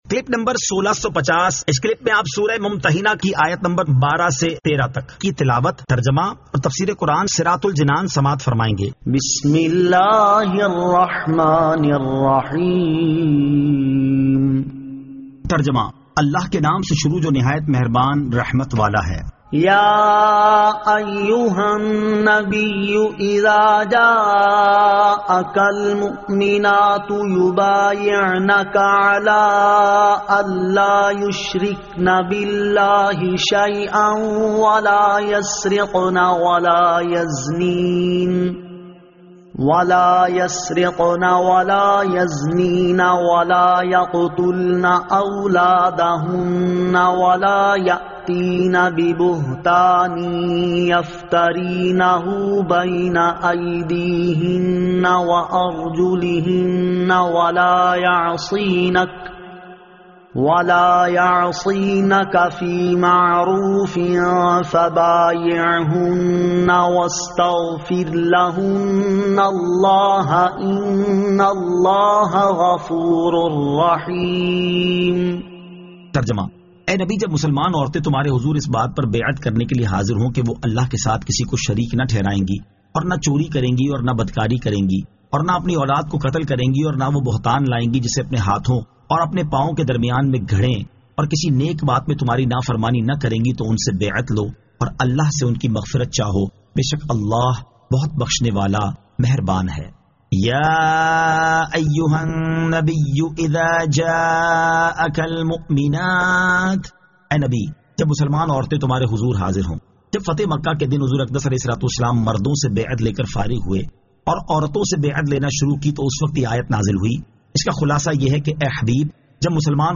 Surah Al-Mumtahinan 12 To 13 Tilawat , Tarjama , Tafseer